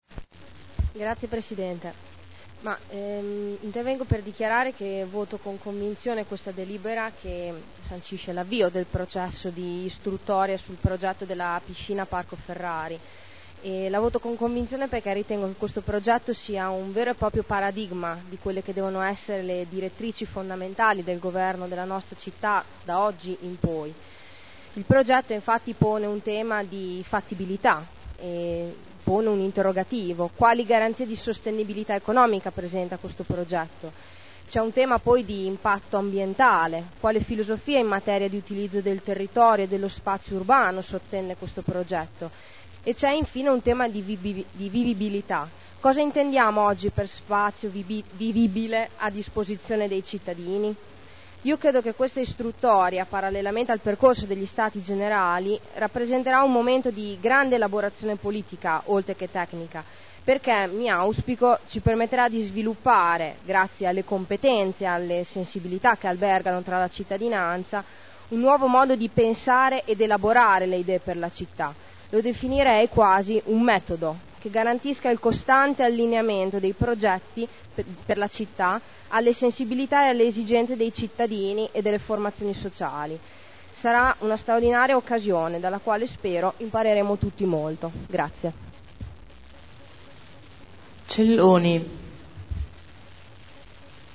Sito Audio Consiglio Comunale